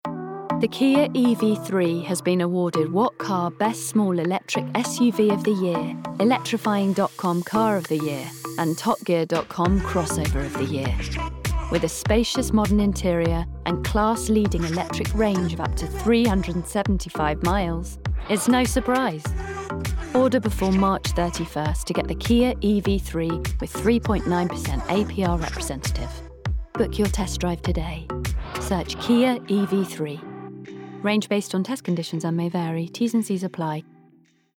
STYLE: Audio Book
30's Neutral/RP/Italian, Versatile/Natural/Assuring